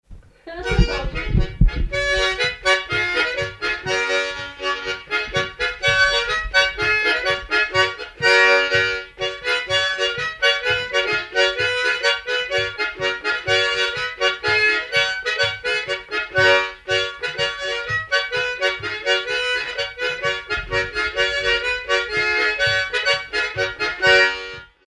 danse : monfarine, montfarine, montferrine, montferine
circonstance : bal, dancerie
Pièce musicale inédite